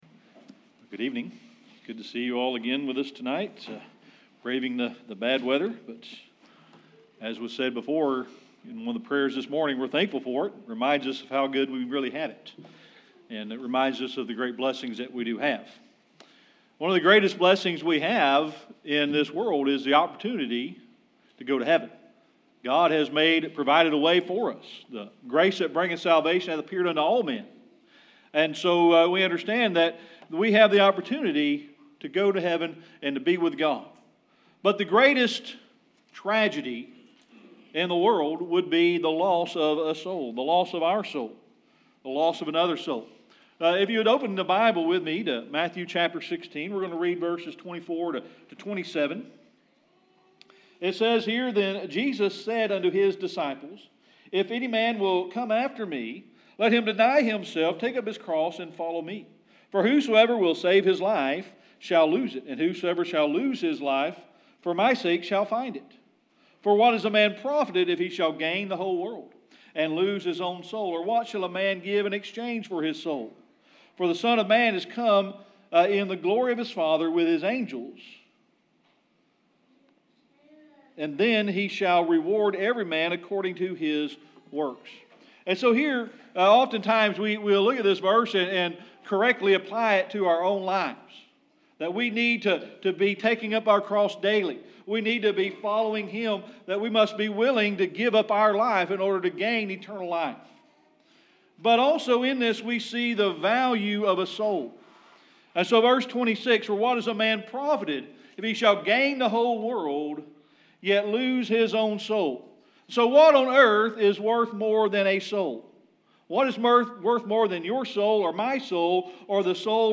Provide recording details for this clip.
Matthew 16:24-27 Service Type: Sunday Evening Worship One of the greatest blessings we have in this world is the opportunity to go to Heaven.